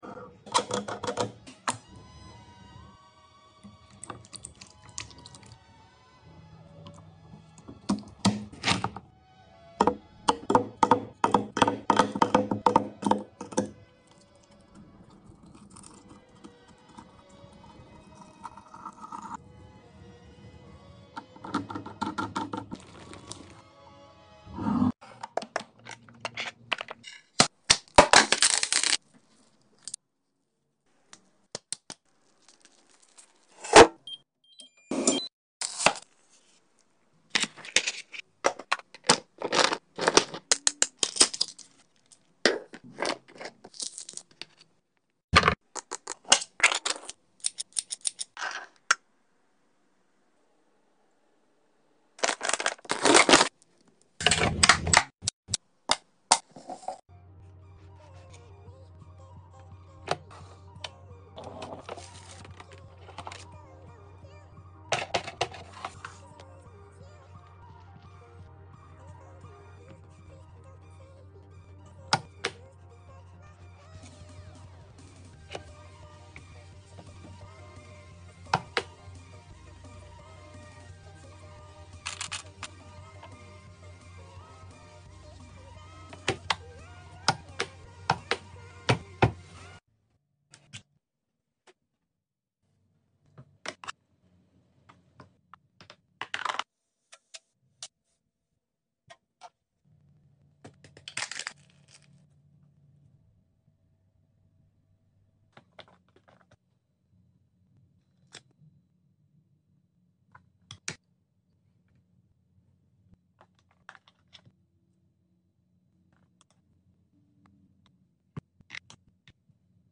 Lunch box and cooking asmr sound effects free download